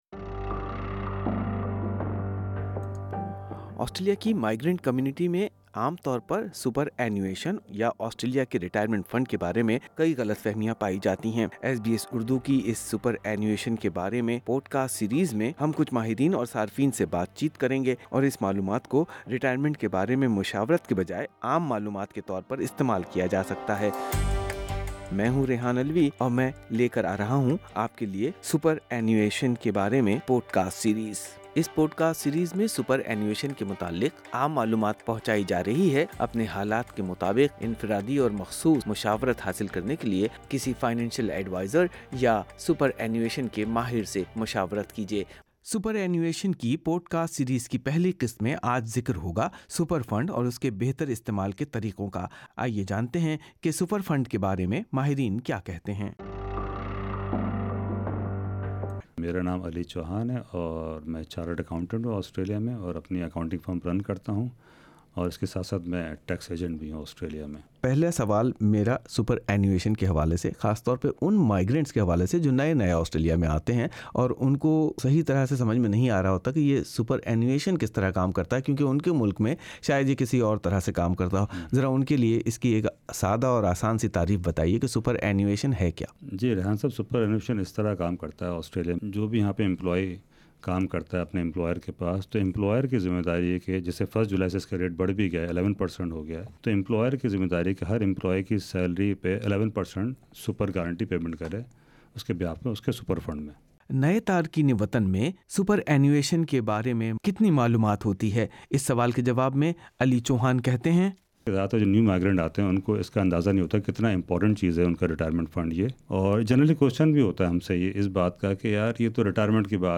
سپراینوایشن کی پوڈکاسٹ سیریز کے پہلے حصے میں جانئے کہ آسٹریلیا میں ریٹائیرمنٹ کے نظام کا لازمی جزو سپر اینیوشن کیسے کام کرتا ہے اور سپر تک رسائی حاصل کرنے کے بارے میں کیا اصول ہیں؟سنئے ماہرین اور صارفین کی رائے۔